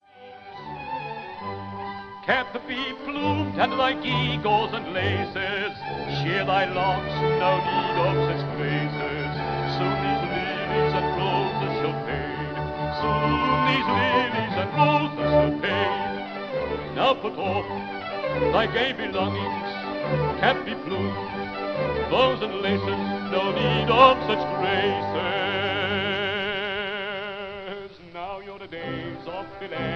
Recorded in 1927
orchestra
baritone
sung in English